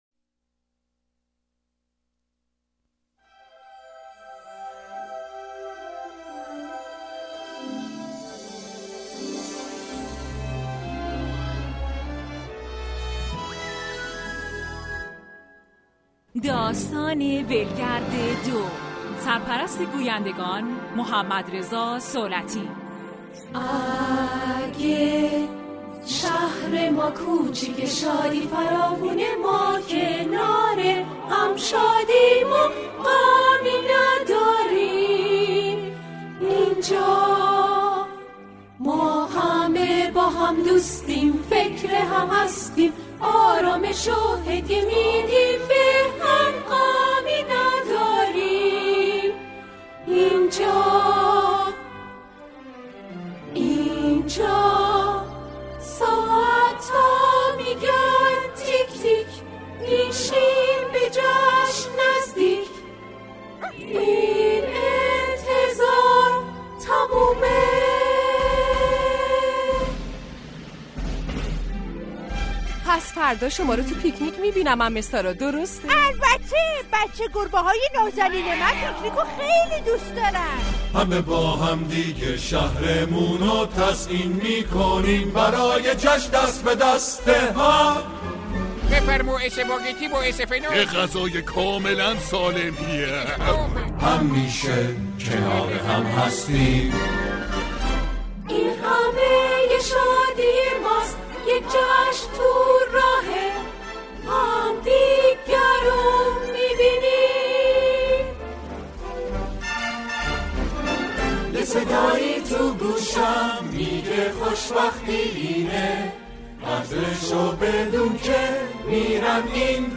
دوبله فارسی
Lady.and.the.Tramp.II.Scamps.Adventure.2001.DUBBED.MP3.Exclusive.mka